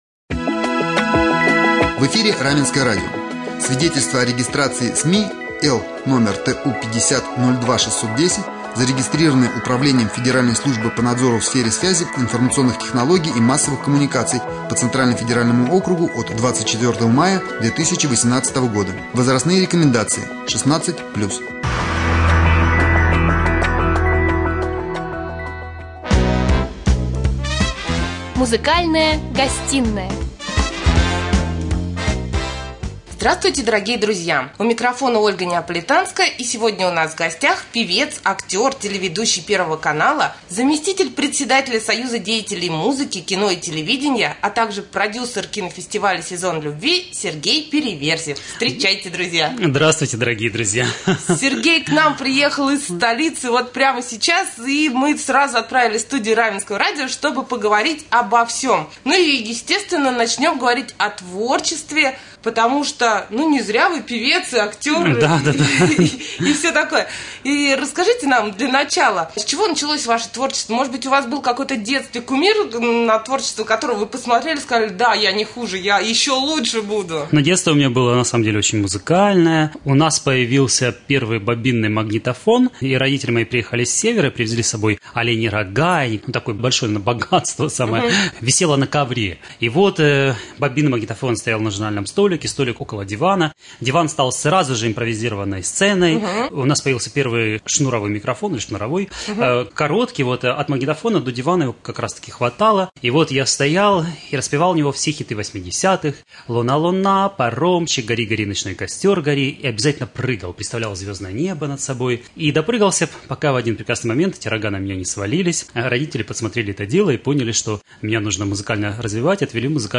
в гостях у Раменского радио